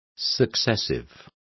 Complete with pronunciation of the translation of successive.